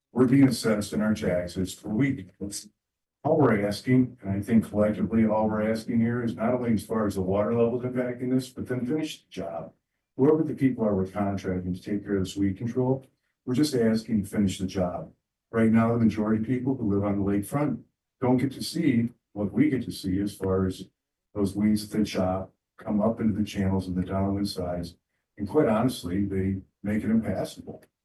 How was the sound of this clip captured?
COLDWATER, MI (WTVB) – The Branch County Board of Commissioners heard concerns about low lake levels, weed control and a lack of action on clogged drains from lake residents during the public comment portion of their meeting Tuesday afternoon.